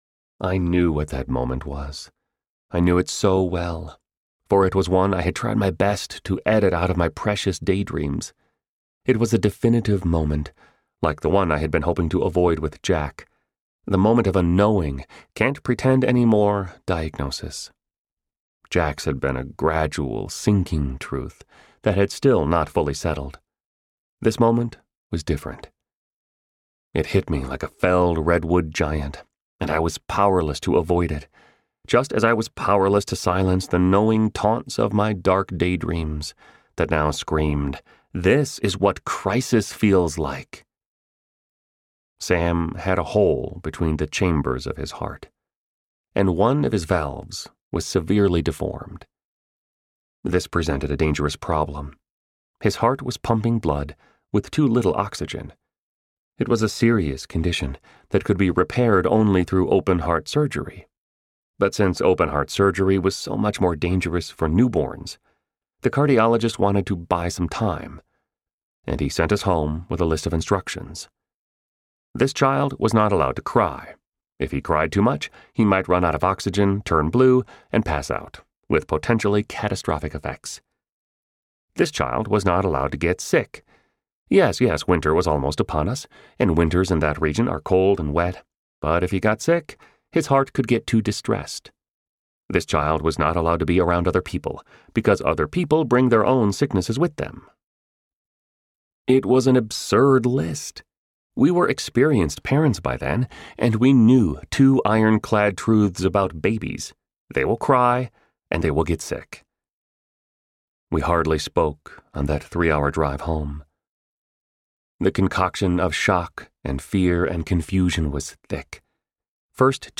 Aching Joy Audiobook
Narrator
5.68 Hrs. – Unabridged